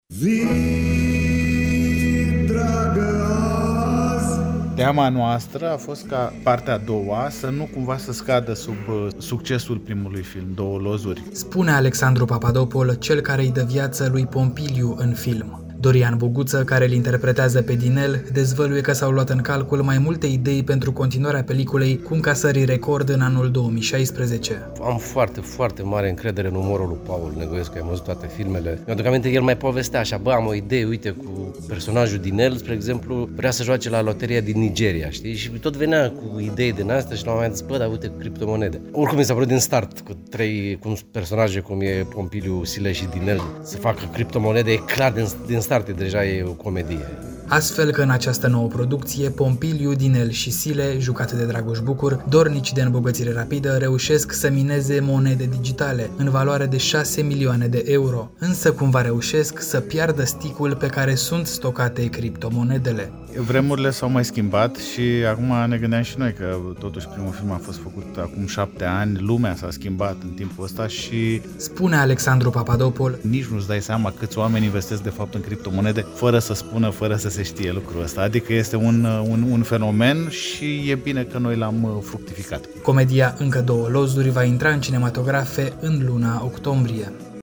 Doi dintre cei trei actori cu roluri principale, Dorian Boguță și Alexandru Papadopol, au vorbit într-un interviu acordat Europa FM la Cluj-Napoca despre noul film, despre cum a fost la filmări și despre reacțiile oamenilor după premieră.